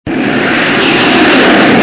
BSG FX - Viper Engine 01 Fly by
BSG_FX-Viper_Engine_01_Fly_By.mp3